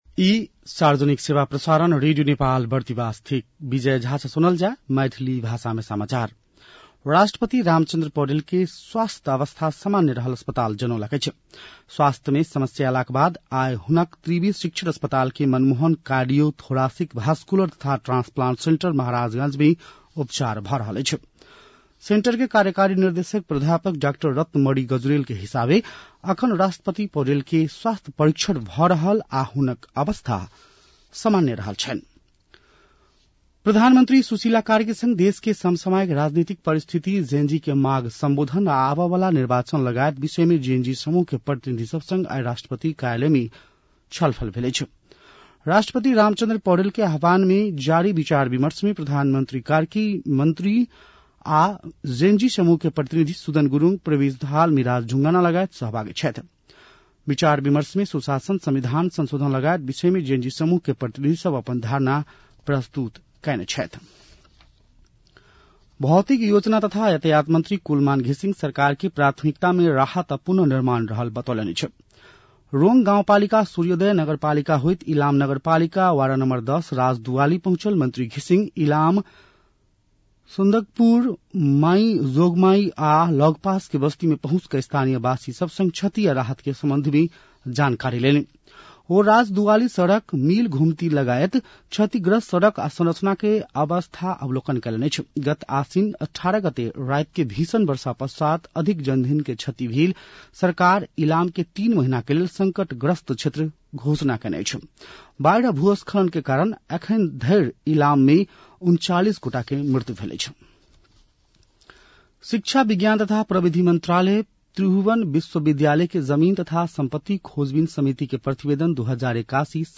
मैथिली भाषामा समाचार : २५ असोज , २०८२